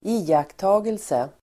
Ladda ner uttalet
iakttagelse substantiv, observation Uttal: [²'i:akta:gelse]